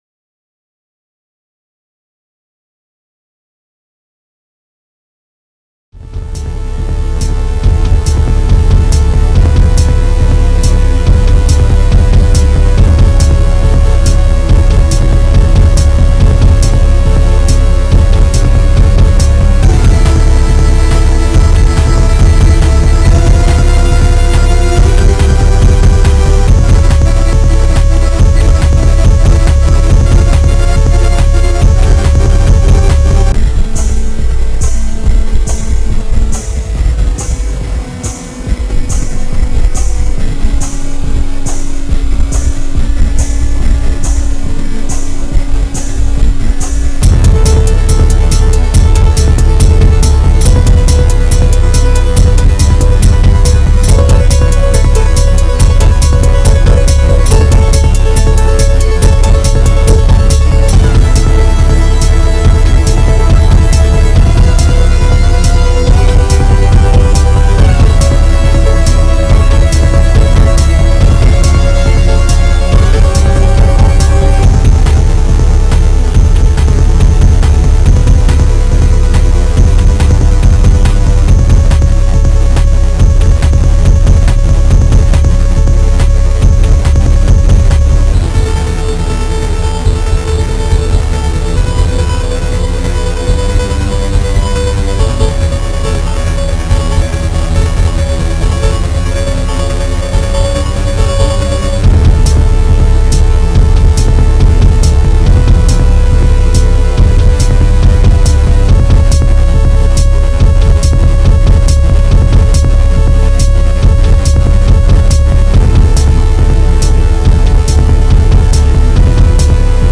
latest song i’m working on in a blitz to finish this album. probably going to re-record a couple of the vocal lines and might fuck a little more with mixing but otherwise it’s done